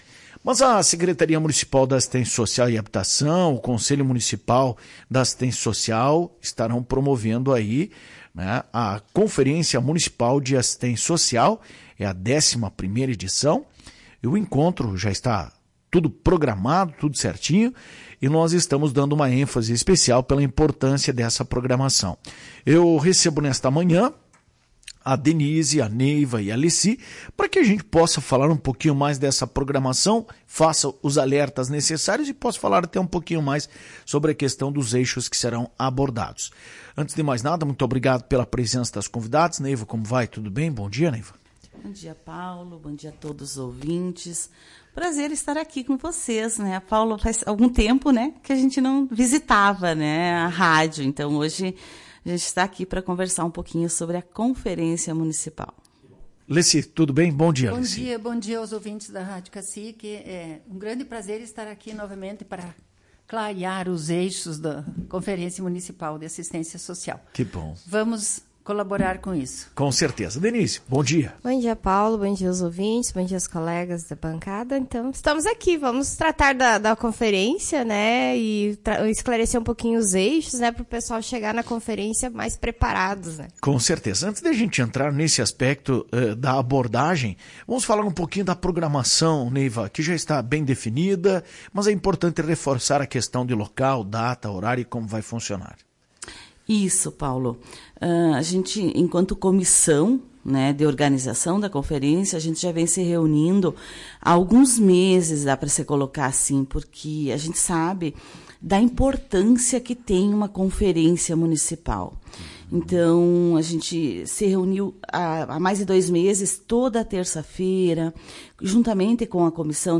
A Conferência gira em torno de cinco Eixos. Em entrevista para Tua Rádio Cacique, as assistentes sociais